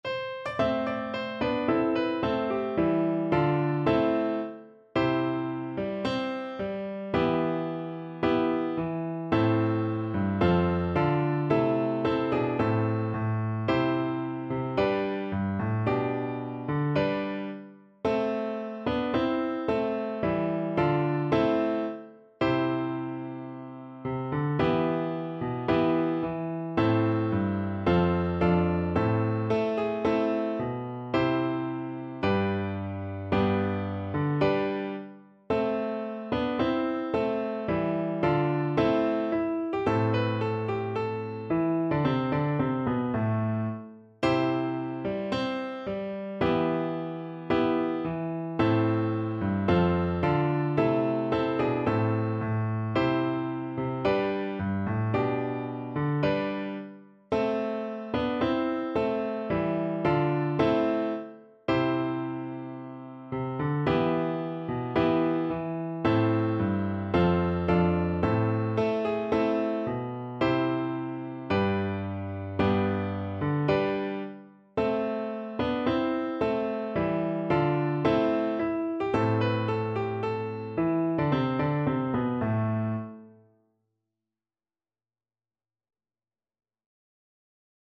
Play (or use space bar on your keyboard) Pause Music Playalong - Piano Accompaniment Playalong Band Accompaniment not yet available reset tempo print settings full screen
4/4 (View more 4/4 Music)
A minor (Sounding Pitch) (View more A minor Music for Cello )
~ = 110 Allegro (View more music marked Allegro)
Traditional (View more Traditional Cello Music)